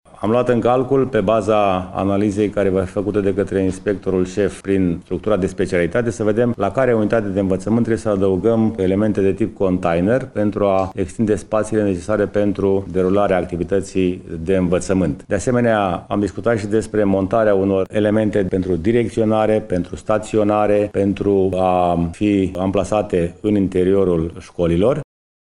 Primaria Brasov va asigura toate dotările, baza materială, produse de igienă și dezinfecție, măști pentru copii si profesori. Primarul municipiul Brașov, George Scripcaru.